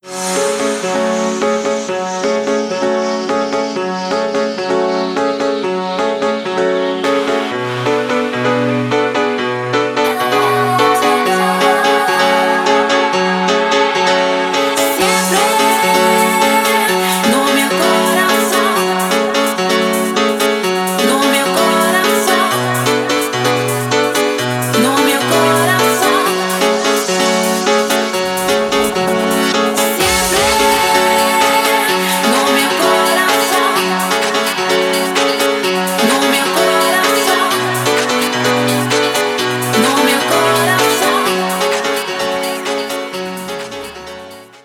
• Качество: 320, Stereo
dance
пианино